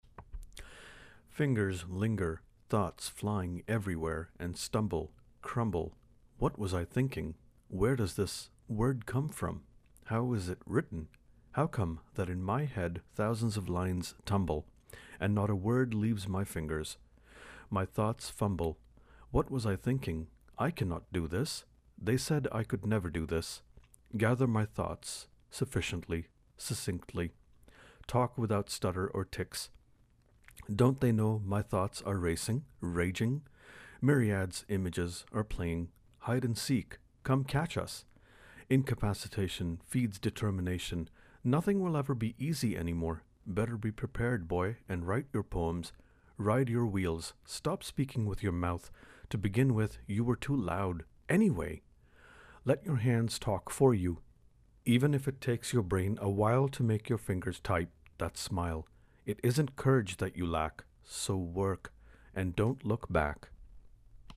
It sounds and reads and soaks in inflection.